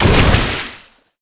water_blast_projectile_spell_03.wav